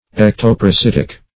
-- Ec`to*par`a*sit"ic , a. [1913 Webster]
ectoparasitic.mp3